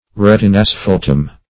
Search Result for " retinasphaltum" : The Collaborative International Dictionary of English v.0.48: Retinasphalt \Ret`in*as"phalt\, Retinasphaltum \Ret`in*as*phal"tum\, n. [Gr.
retinasphaltum.mp3